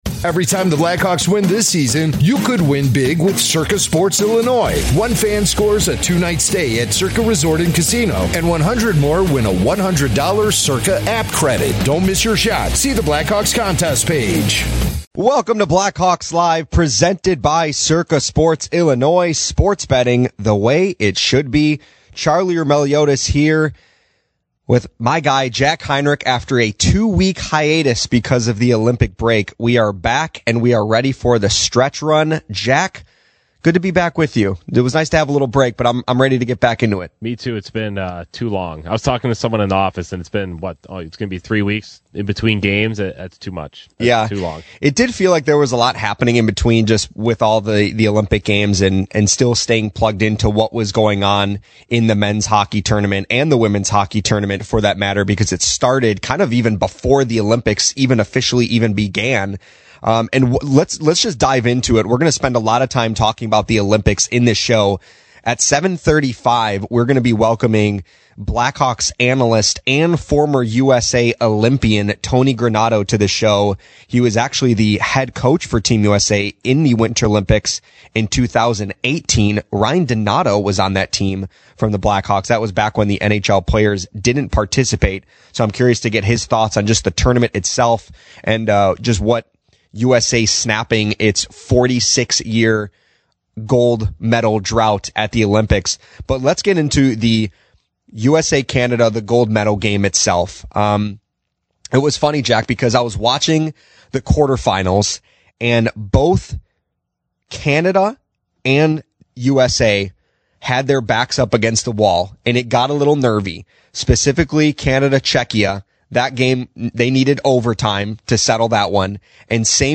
Later, Blackhawks TV analyst and former U.S. Olympian and head coach Tony Granato joins the show to reflect on the Americans’ first gold medal since 1980, share what it means to represent the red, white and blue, and offer insight into the tournament.